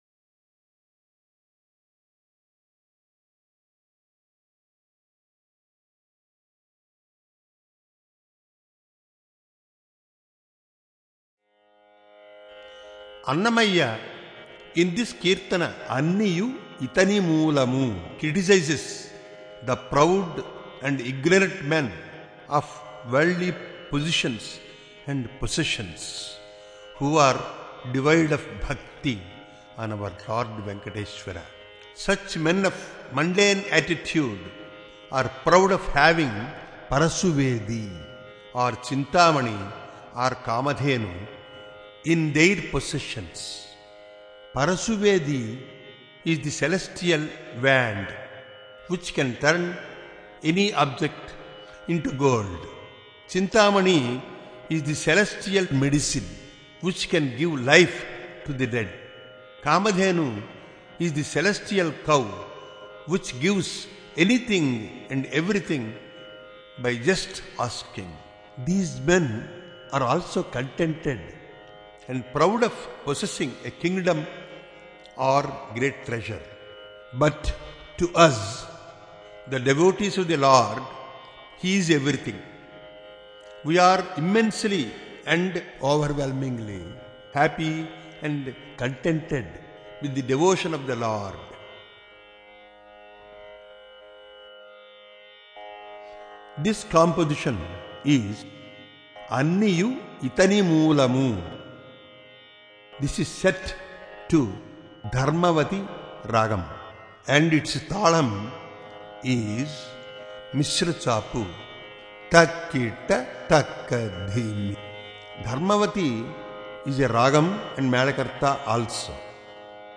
సంగీతం
పాడినవారు సంగీతం నూకల చిన సత్యనారయణ